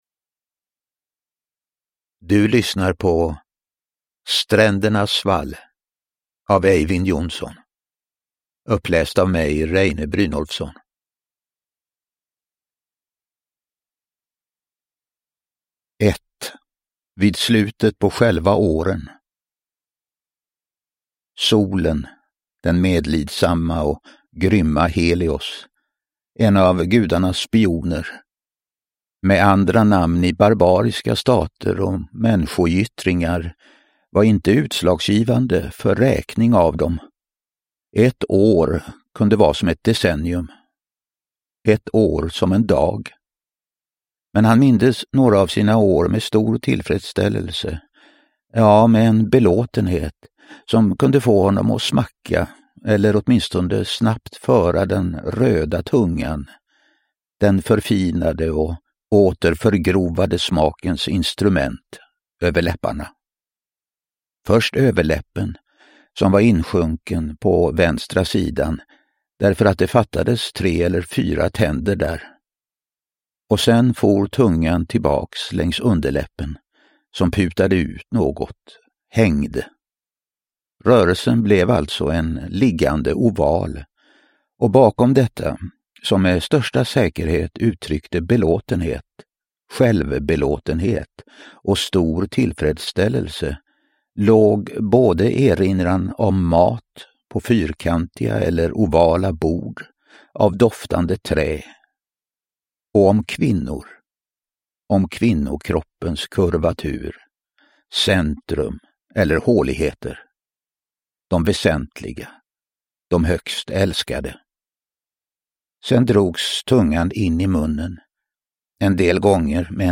Strändernas svall – Ljudbok – Laddas ner
Uppläsare: Reine Brynolfsson